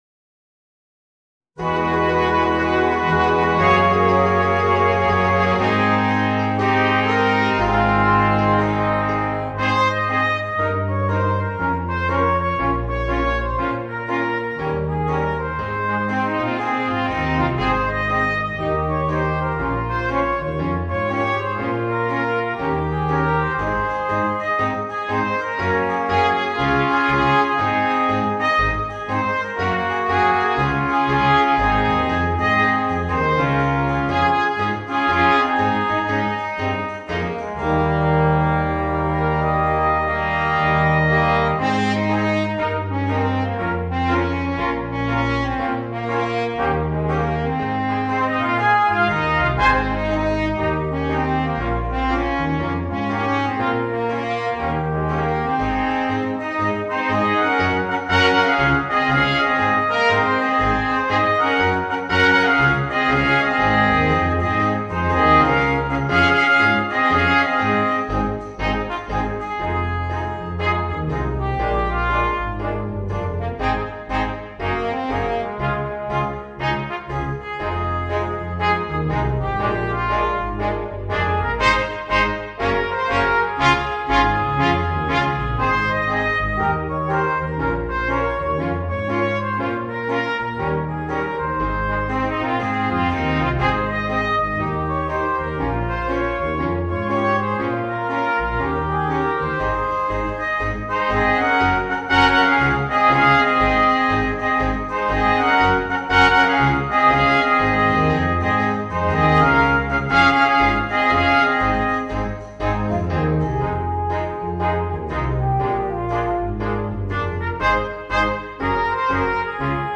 For Brass Quartet